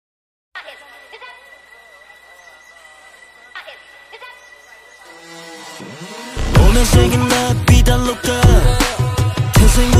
hn_mus125_stride3_Gm